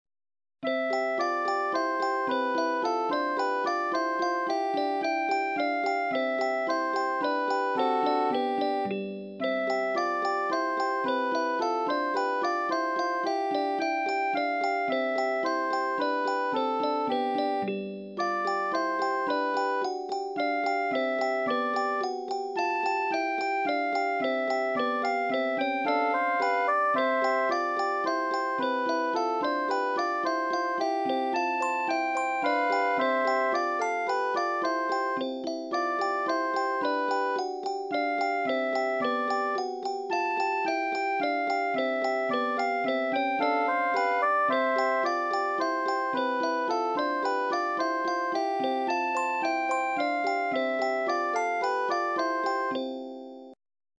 Esempi di musica realizzata con il sistema temperato